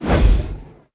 sfx_skill 11_1.wav